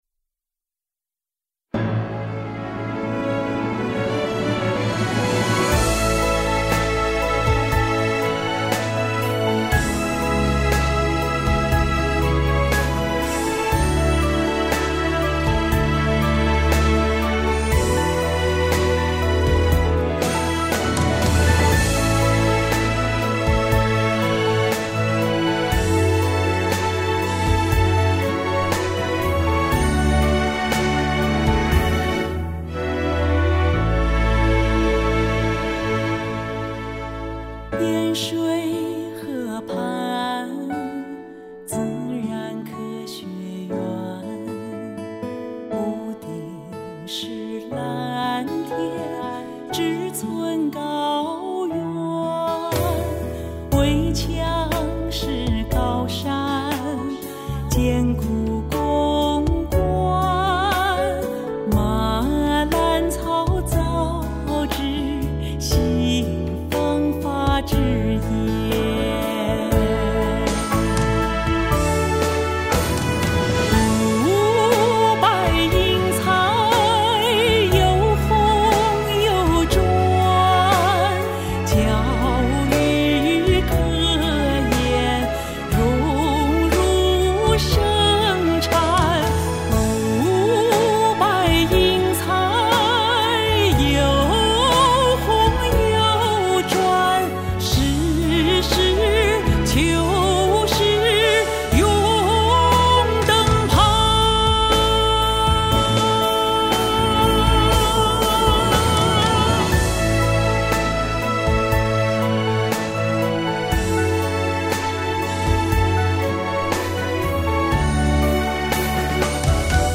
【庆七十华诞 唱响北理工】系列原创歌曲—《明理精工》